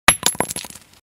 Tarkov Grenade Sound Effect Free Download
Tarkov Grenade